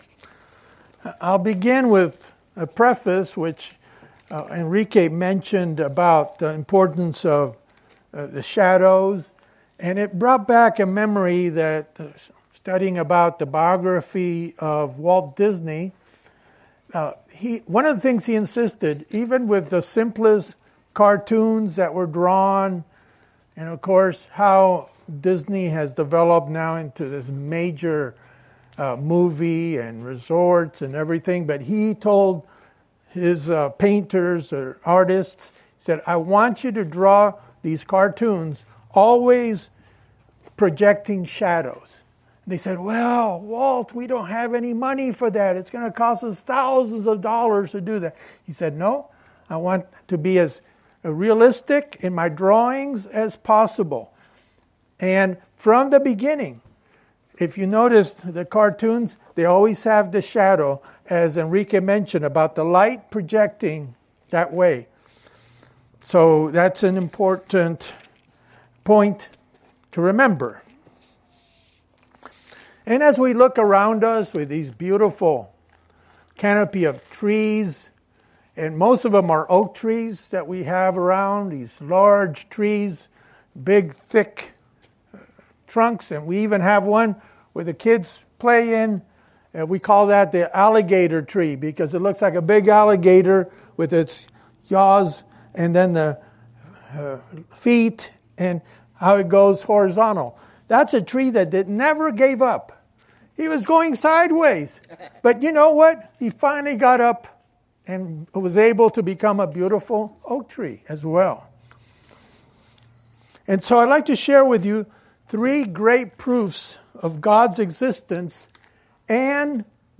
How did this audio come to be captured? We have three gifts from God that prove His existence, goodness and love for us. During this Sabbath-In-The-Park, we explore these three gifts God gave to us that proves His existence and benevolence.